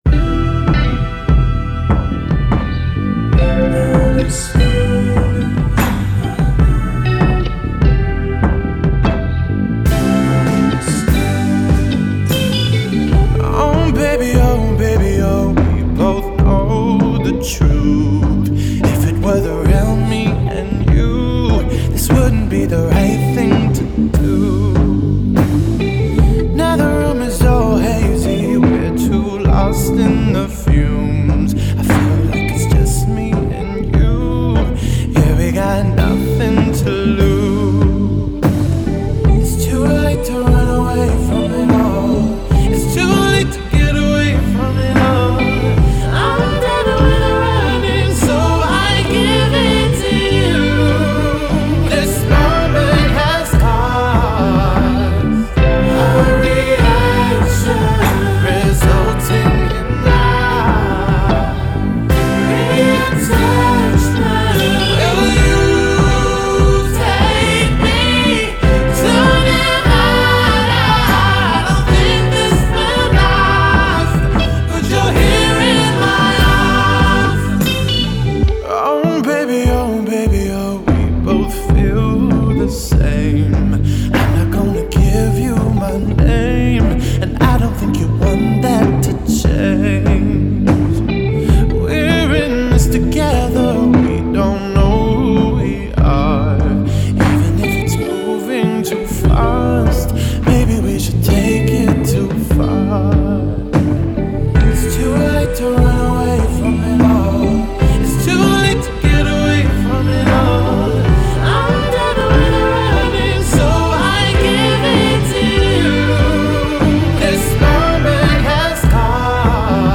songs of deep melismatic male longing